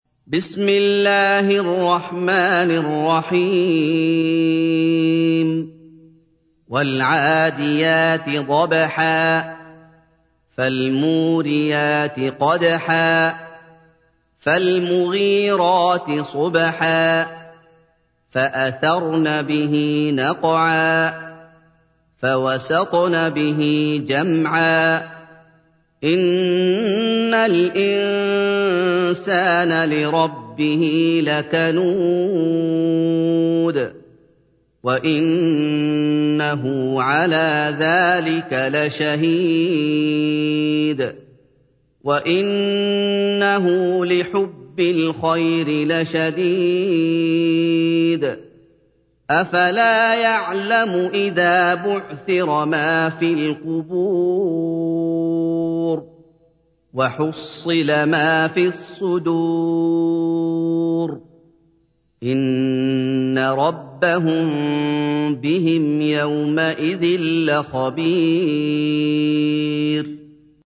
تحميل : 100. سورة العاديات / القارئ محمد أيوب / القرآن الكريم / موقع يا حسين